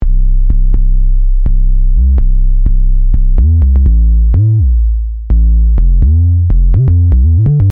808 Bassは、TR-808由来の重低音ベースサウンドを生成するタイプです。
ヒップホップやトラップなどのジャンルで定番のベースサウンドを手軽に取り入れられます。
▼808 Bassサウンド
LogicPro12_808Bass.mp3